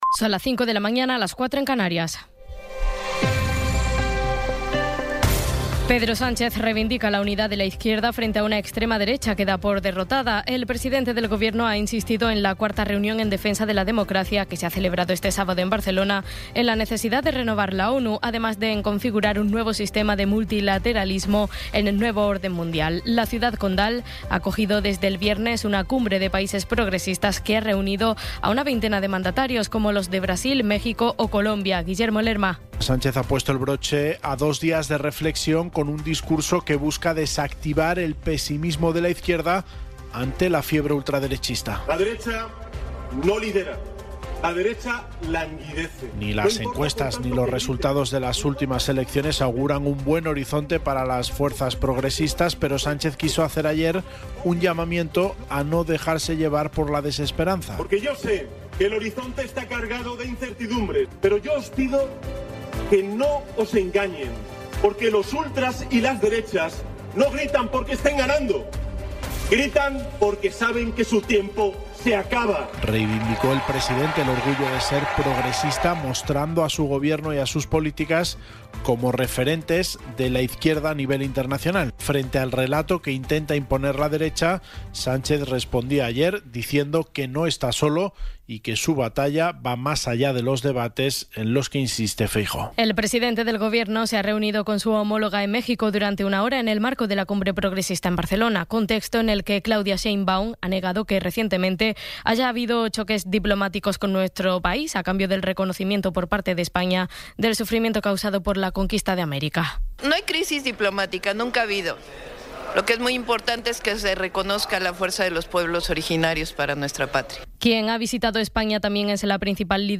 Resumen informativo con las noticias más destacadas del 19 de abril de 2026 a las cinco de la mañana.